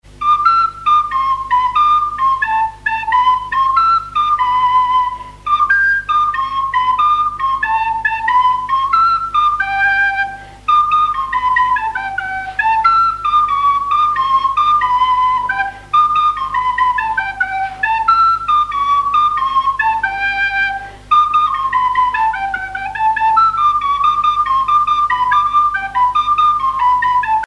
danse : quadrille : promenade
airs de danse à la flûte
Pièce musicale inédite